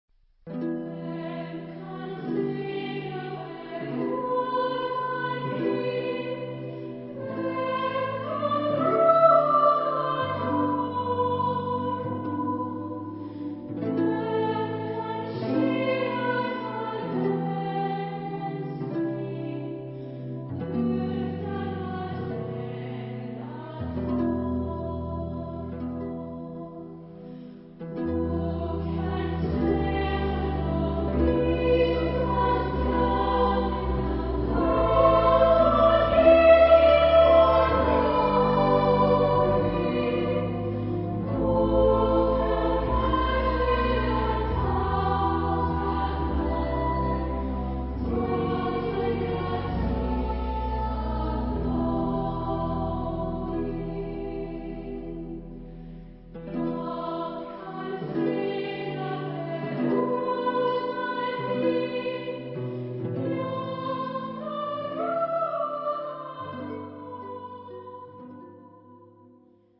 Genre-Style-Forme : Folklore ; Profane
Caractère de la pièce : triste
Type de choeur : SSA  (3 voix égales de femmes )
Instrumentation : Piano  (1 partie(s) instrumentale(s))
Tonalité : mi mineur